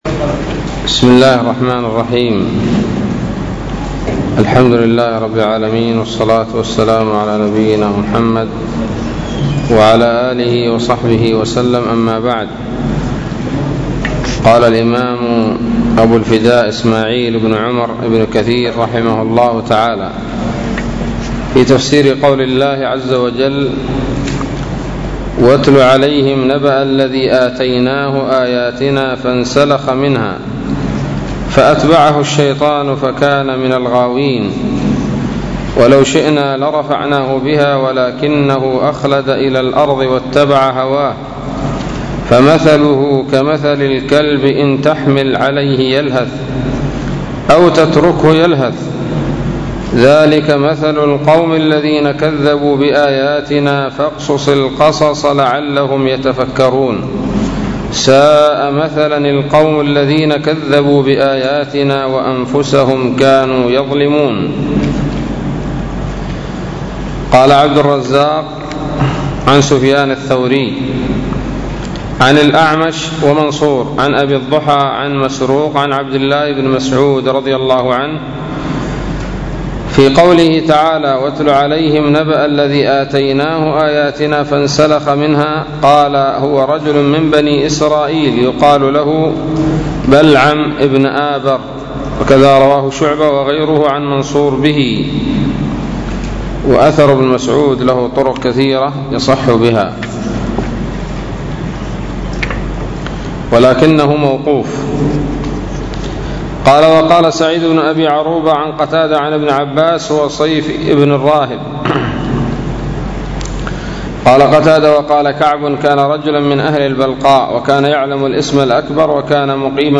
007 سورة الأعراف الدروس العلمية تفسير ابن كثير دروس التفسير
الدرس الرابع والستون من سورة الأعراف من تفسير ابن كثير رحمه الله تعالى